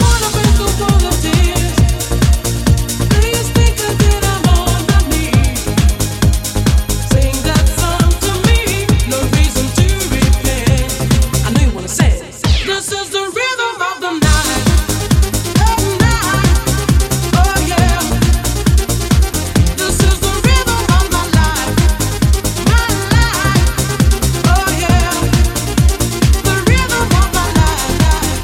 Genere: pop, dance, elettronica, successi, remix